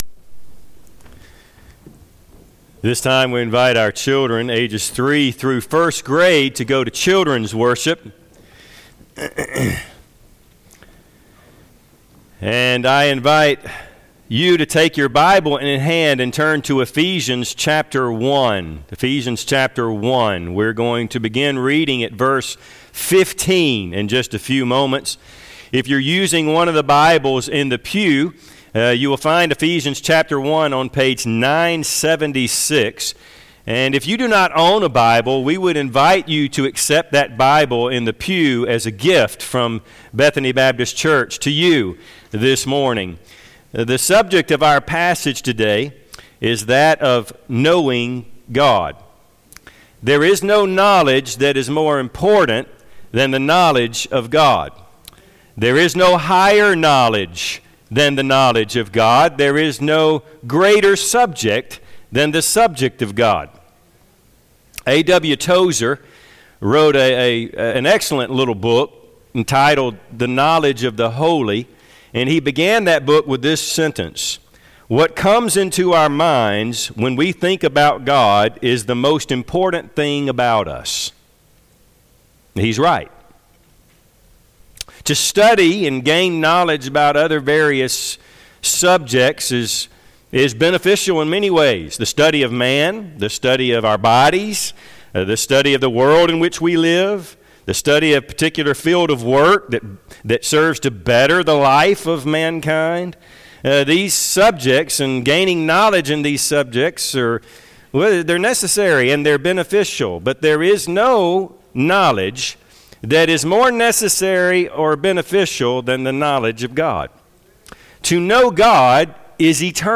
Bible Text: Ephesians 1:15-18a | Preacher